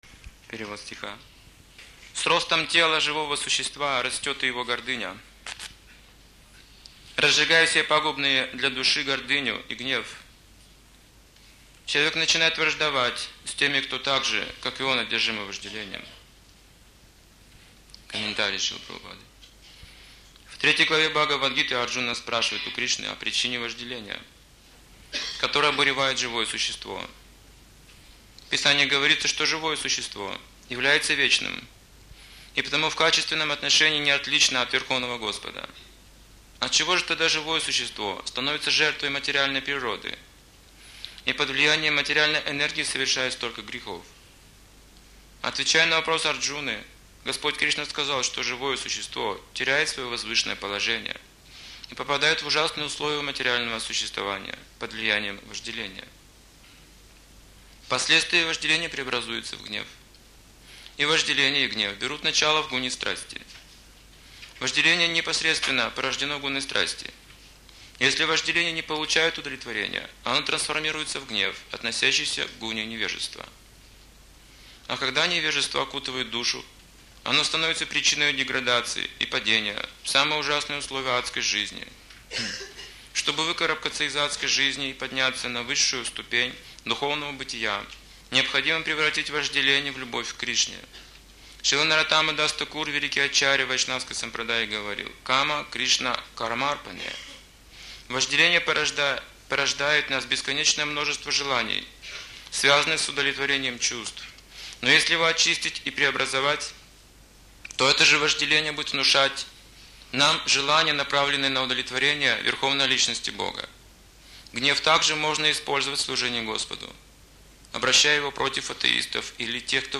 Темы, затронутые в лекции: В чем состоит источник нашего вкуса?